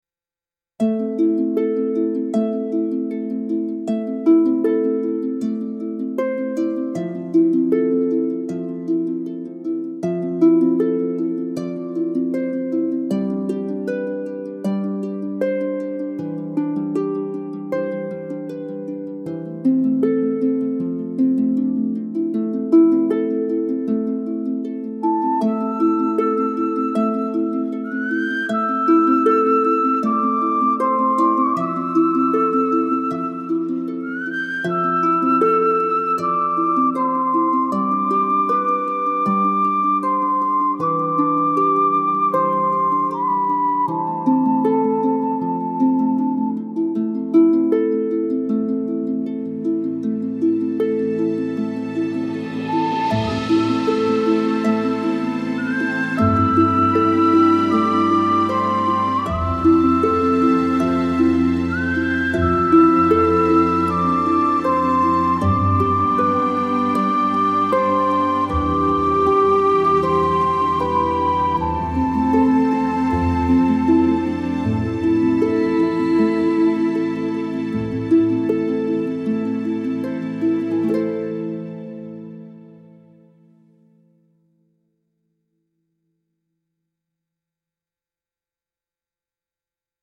celtic spiritual music with tin whistle, harp and flowing strings